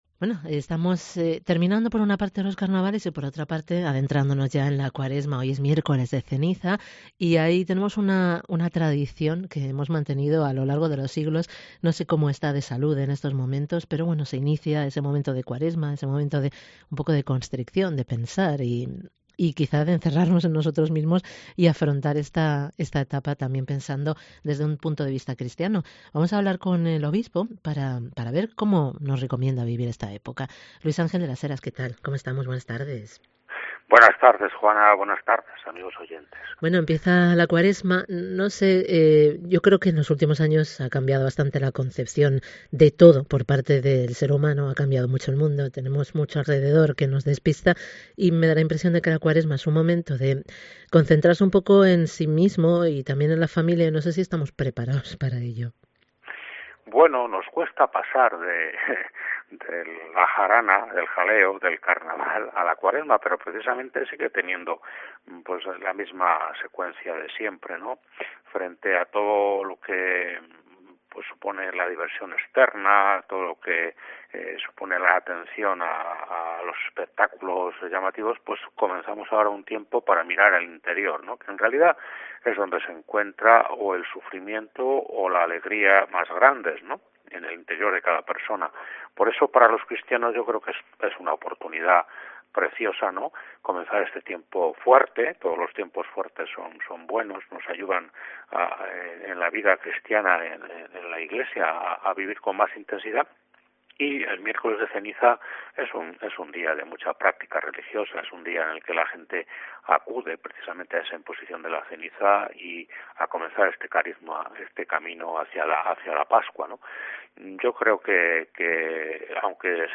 ENTREVISTA con Luis Ángel de las Heras en Miércoles de Ceniza